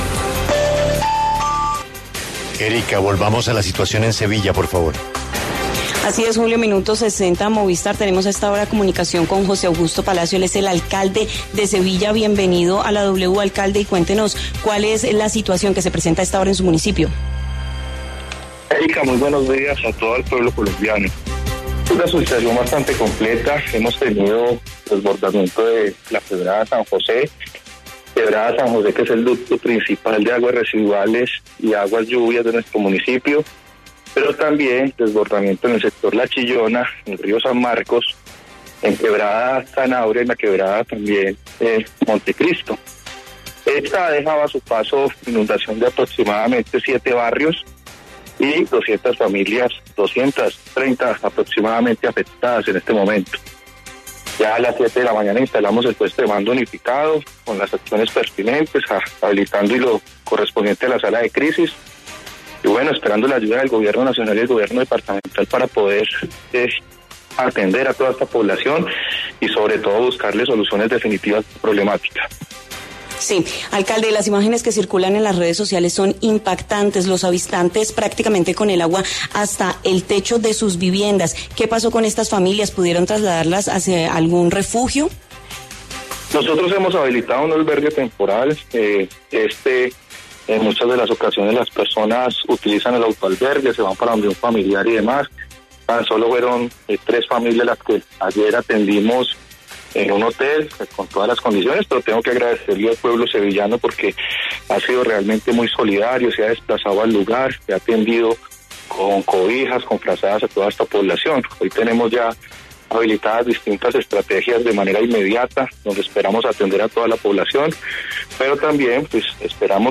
En diálogo con La W, el alcalde de Sevilla, José Augusto Palacio, reveló que al menos cinco barrios del municipio presentan emergencias por inundaciones.